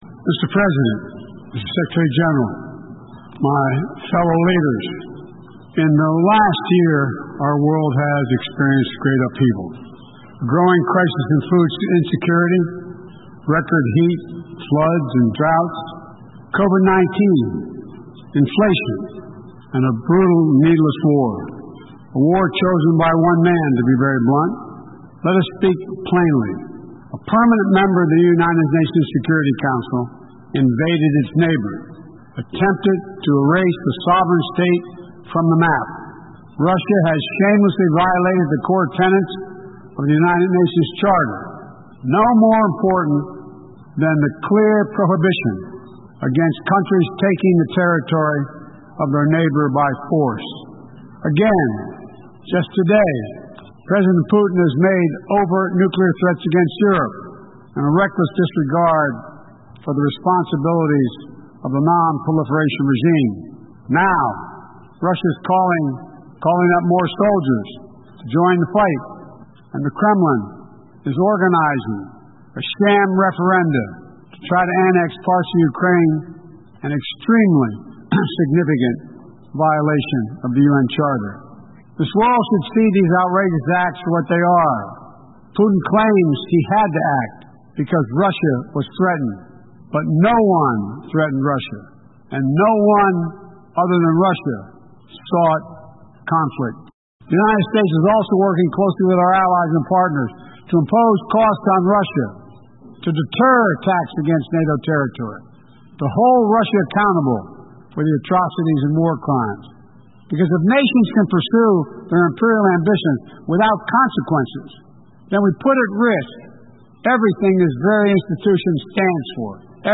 WASHINGTON DC - UMongameli waseMelika uMnu Joe Biden wethule amazwi akhe emhlanganweni weUnited Nations General Assembly ngoLwesithathu lapho achothoze khona eleRussia ngokwethusela ukuhlasela amanye amazwe ngezikhali zembhubhiso, i-nuclear, alubana ethe angenela empini yalo leleUkraine....
Amazwi kaMongameli Joe Biden